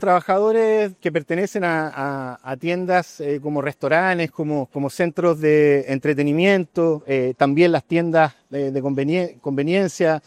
El subsecretario del Trabajo, Pablo Chacón, detalló que hay excepciones: Pueden trabajar quienes desempeñen en clubes, restaurantes, establecimiento de entretenimientos como cines, espectáculos en vivo, discotecas o pubs.
cuna-comercio-subsecretario.mp3